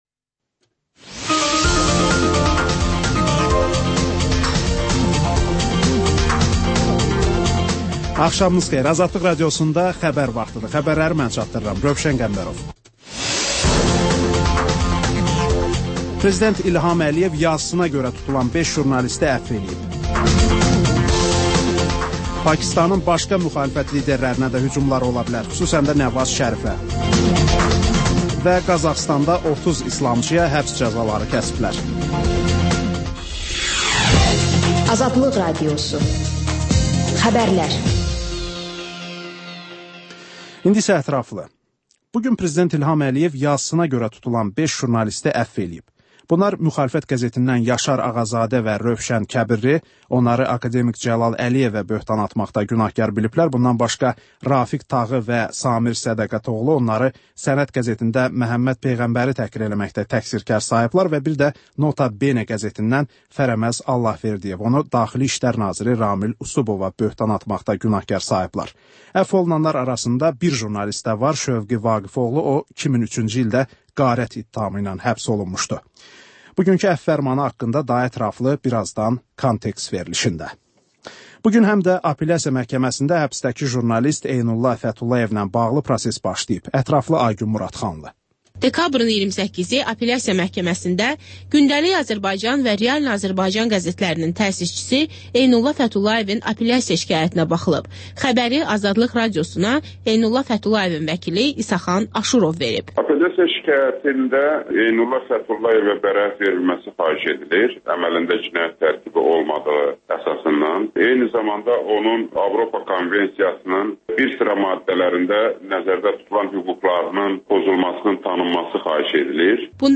Xəbərlər, müsahibələr, hadisələrin müzakirəsi, təhlillər, sonda XÜSUSİ REPORTAJ rubrikası: Ölkənin ictimai-siyasi həyatına dair müxbir araşdırmaları